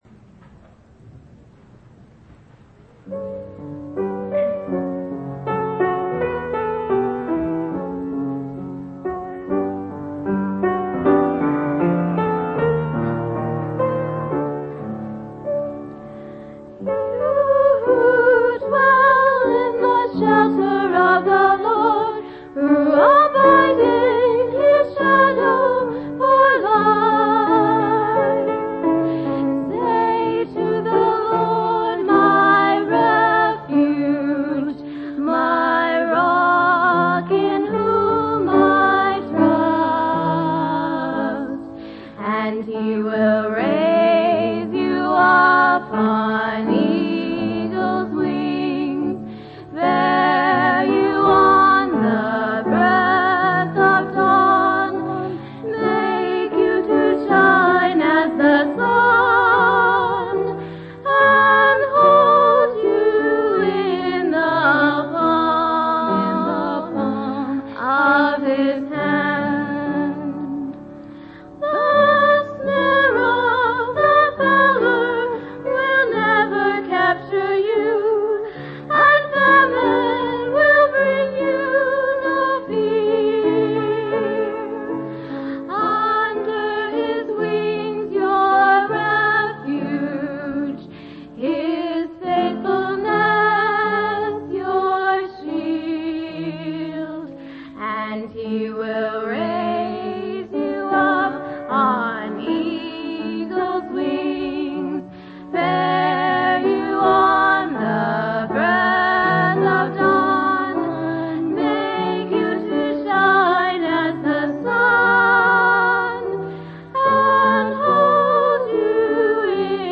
8/7/1999 Location: Missouri Reunion Event